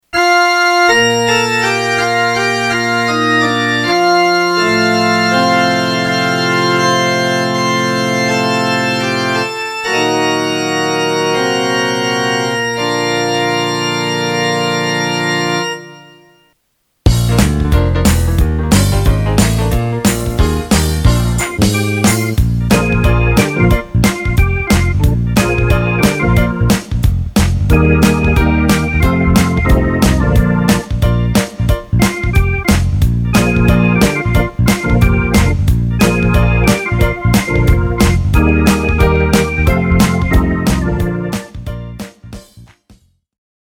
(zonder zang)